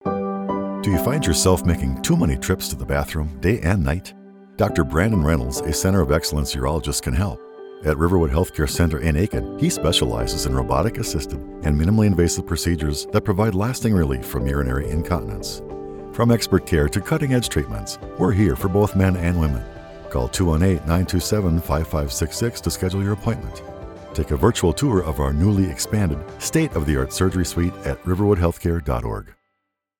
Middle Aged
With his natural and rich bass, he effortlessly shifts from a conversational and engaging style to a powerful promo style.